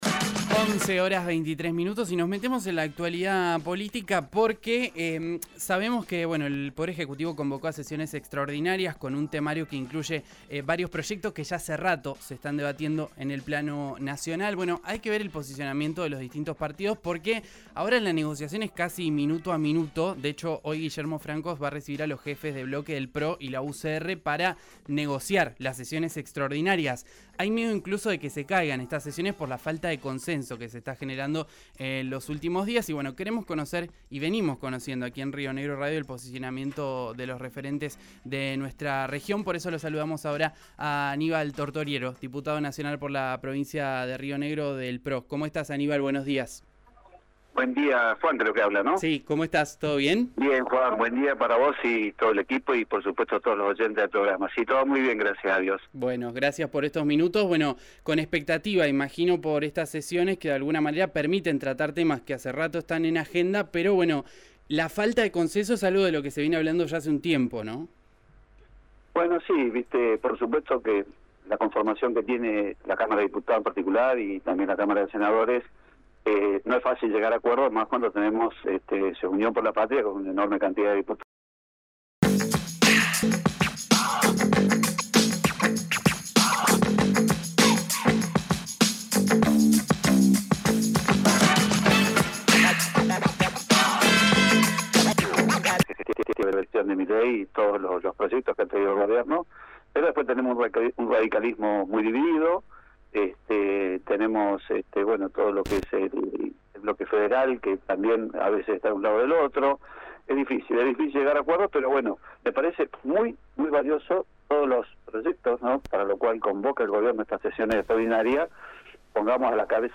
Escuchá a Aníbal Tortoriello en el aire de RÍO NEGRO RADIO https